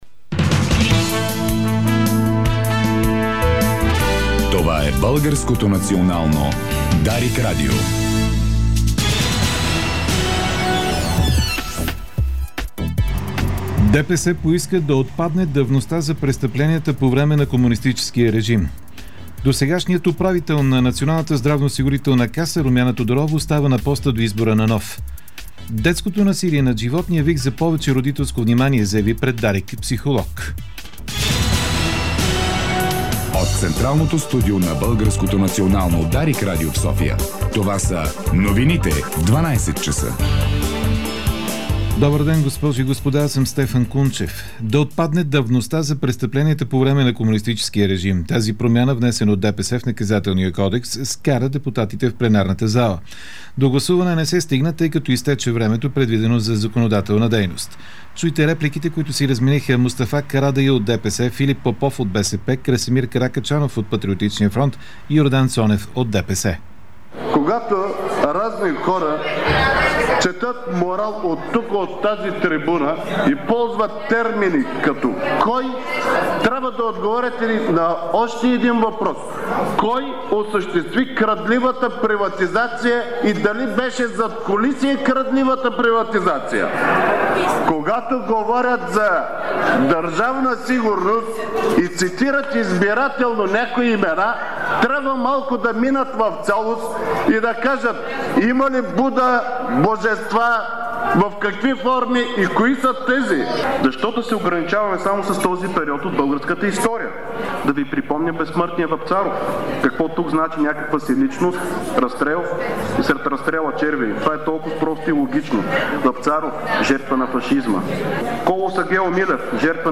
Обедна информационна емисия